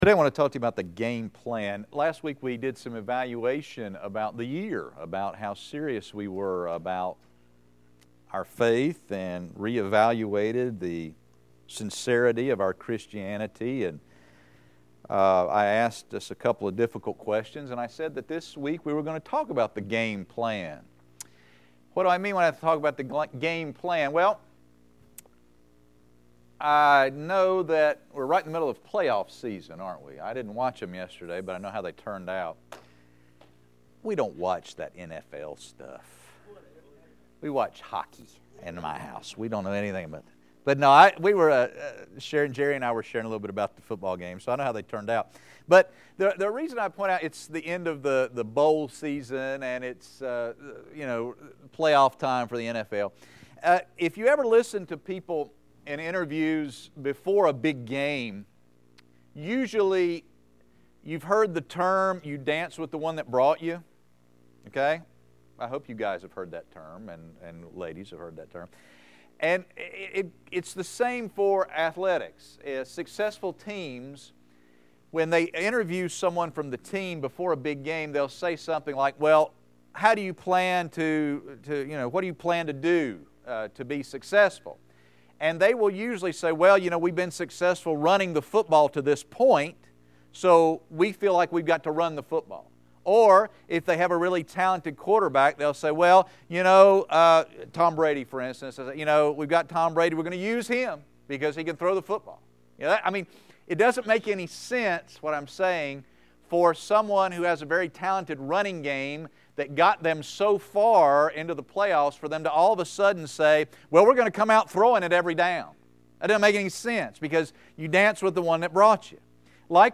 Pastor's Sermon Messages